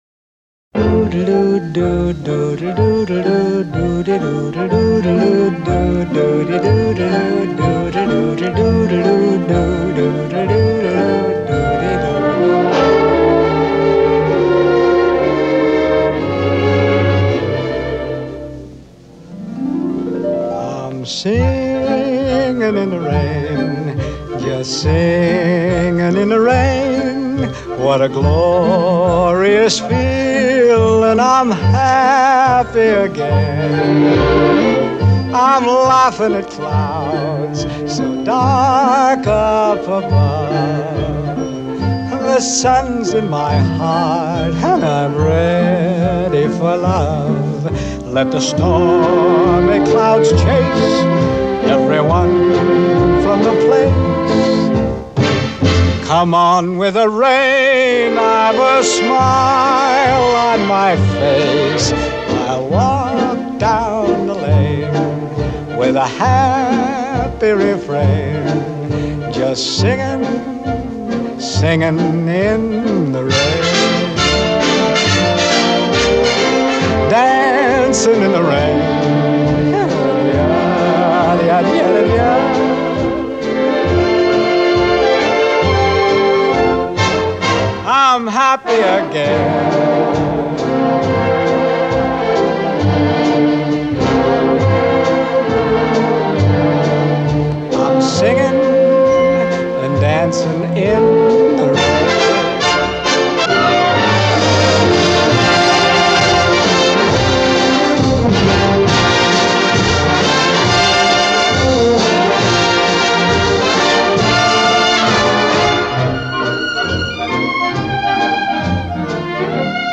sheer exuberance